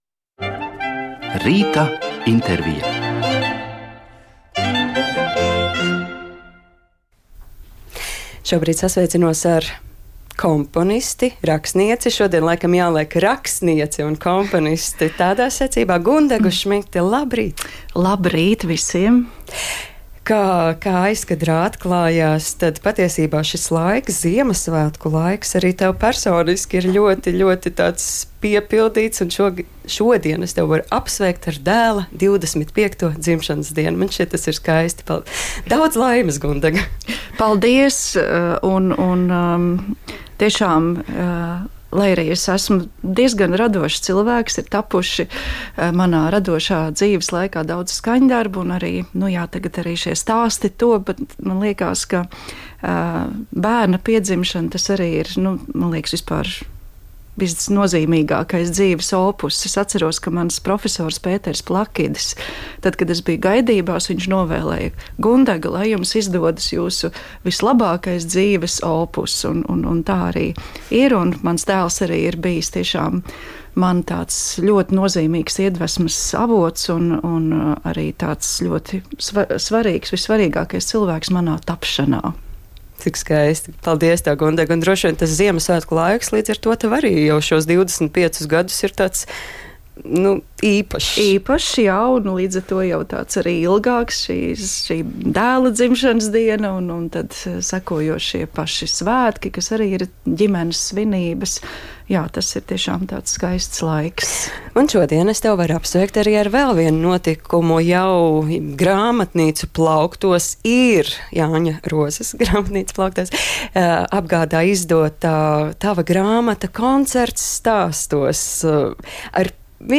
Intervija